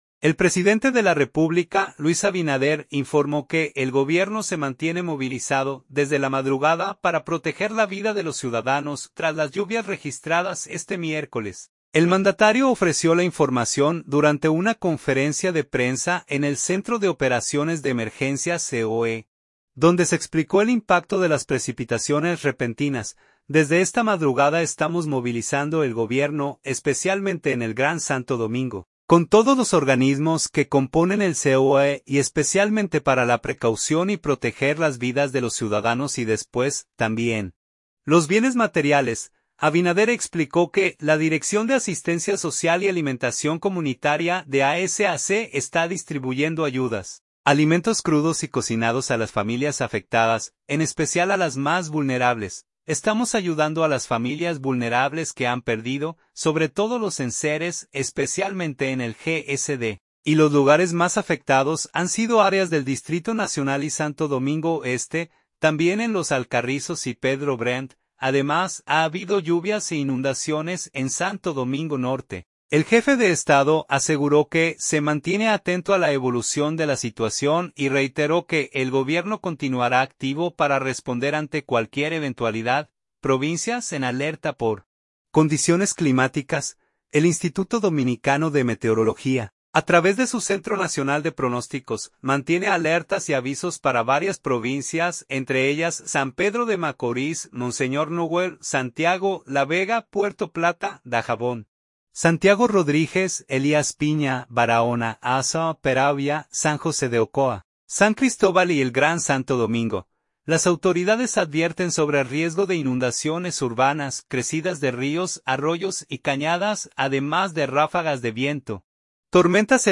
El mandatario ofreció la información durante una conferencia de prensa en el Centro de Operaciones de Emergencias (COE), donde se explicó el impacto de las precipitaciones repentinas.